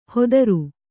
♪ hodaru